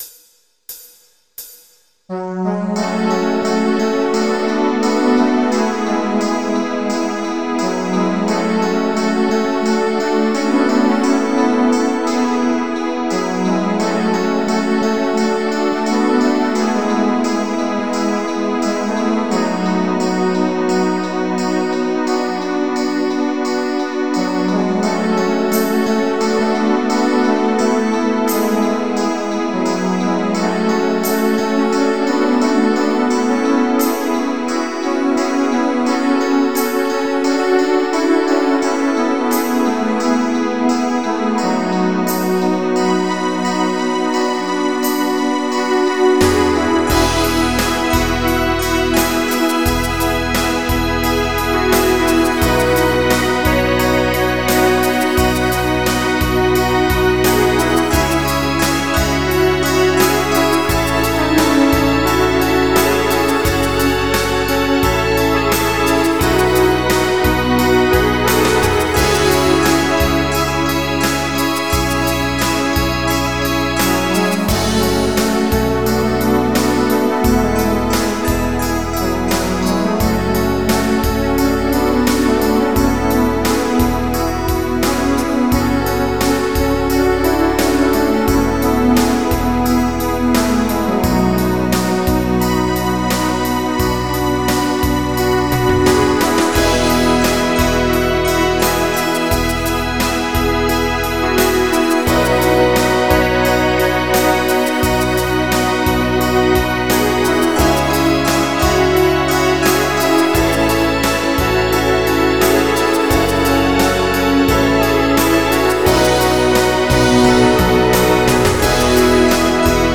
Pop
MIDI Music File